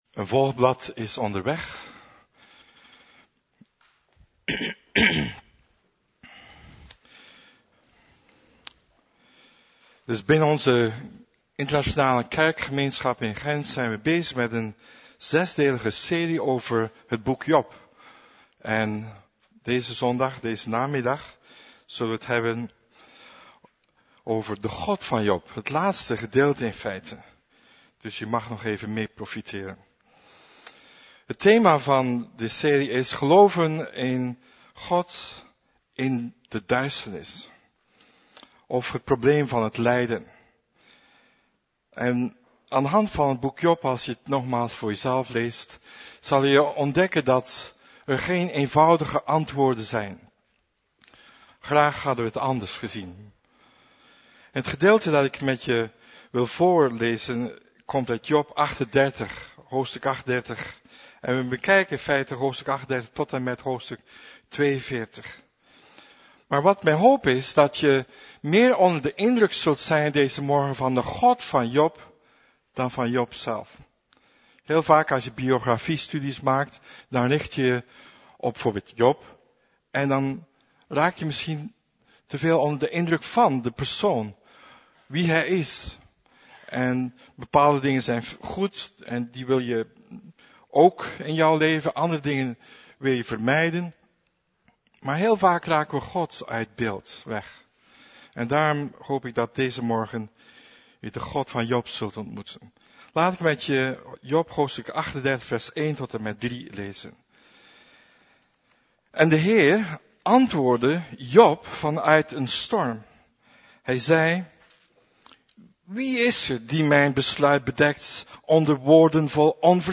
Preek - Levende Hoop